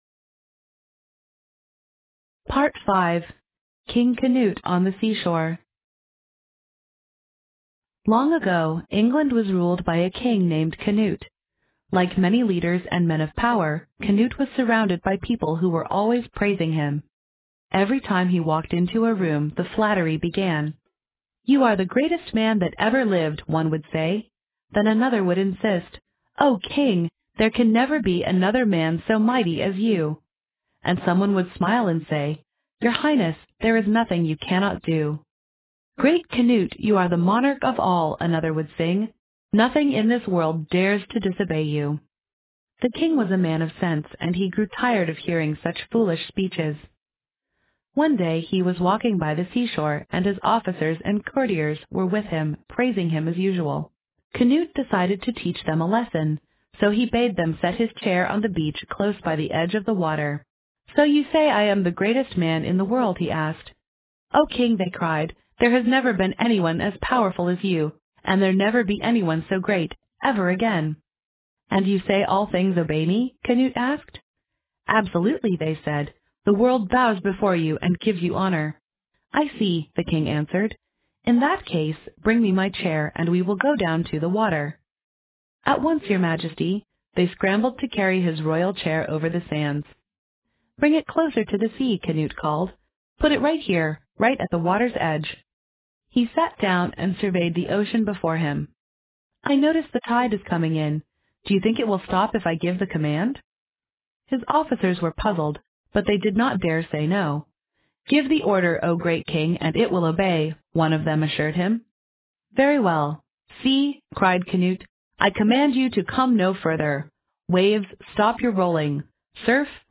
在线英语听力室英语童话故事：克努特国王在海滨 King Canute on the Seashore的听力文件下载,英语有声读物-在线英语听力室